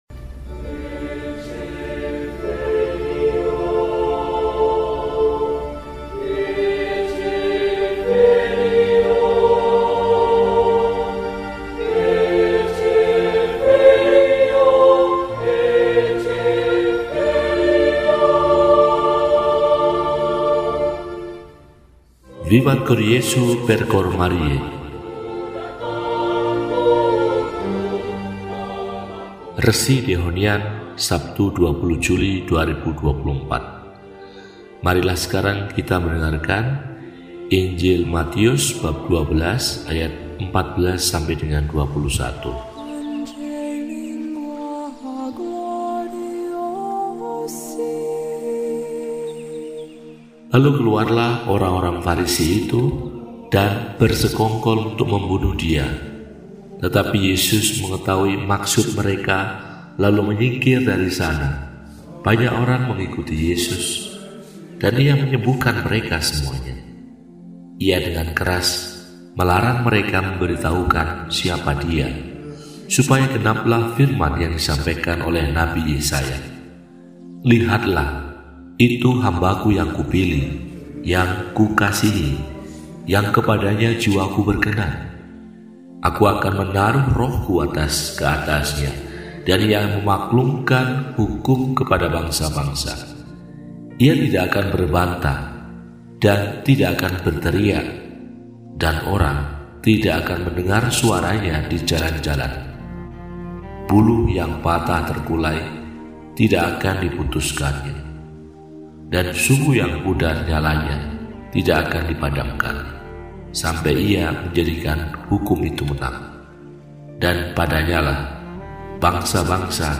Sabtu, 20 Juli 2024 – Hari Biasa Pekan XV – RESI (Renungan Singkat) DEHONIAN